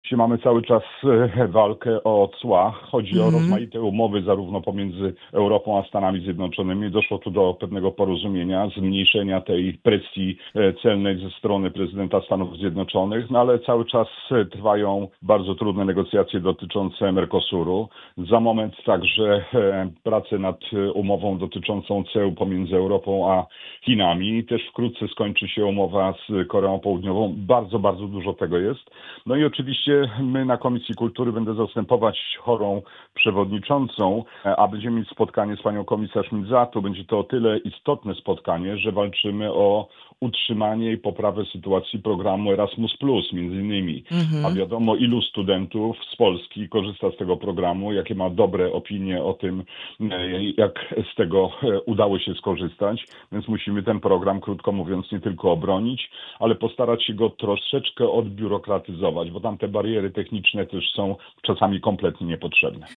W rozmowie „Poranny Gość” z europosłem Bogdanem Zdrojewskim rozmawialiśmy o wizycie prezydenta Karola Nawrockiego w USA, relacjach z rządem, a także o zmianach i wyborach w PO.